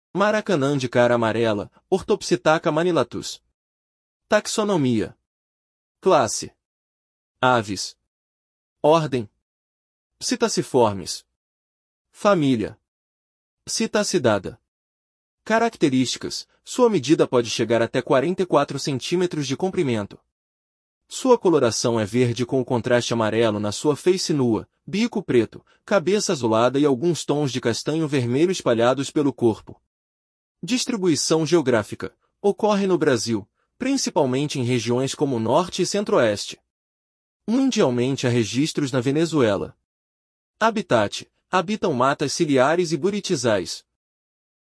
Maracanã-de-cara-amarela (Orthopsittaca manilatus)